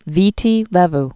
(vētē lĕv)